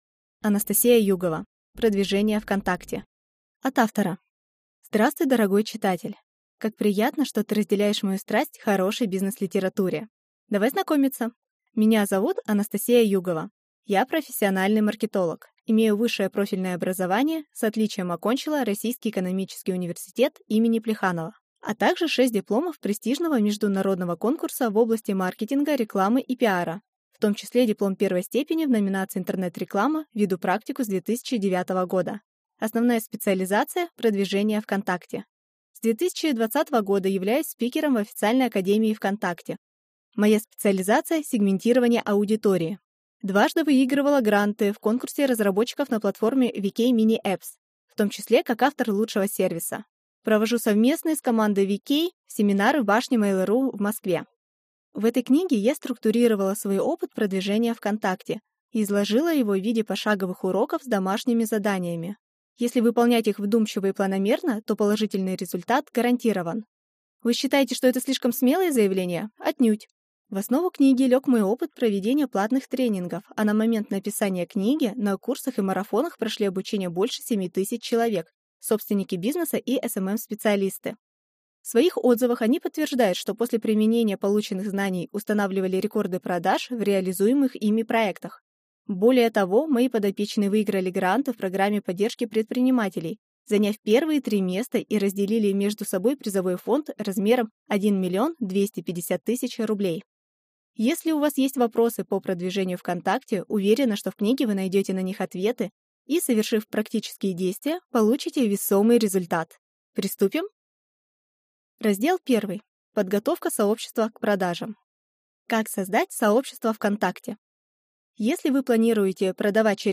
Аудиокнига Продвижение ВКонтакте | Библиотека аудиокниг